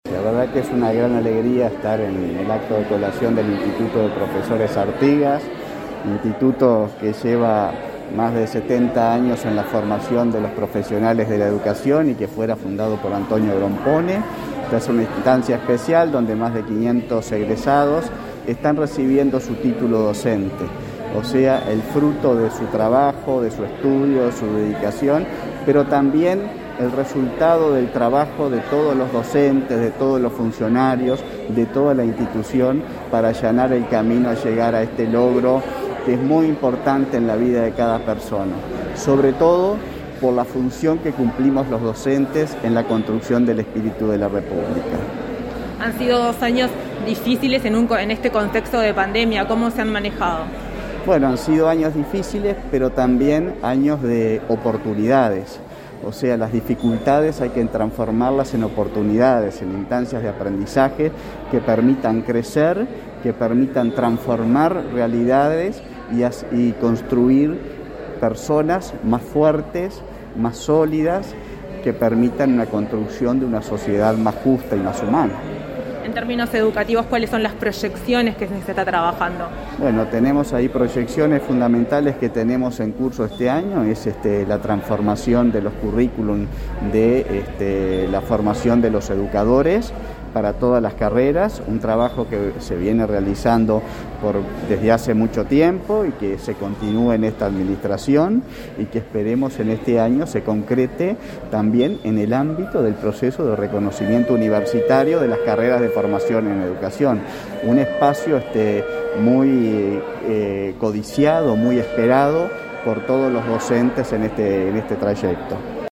Entrevista al presidente del Consejo de Formación en Educación de la ANEP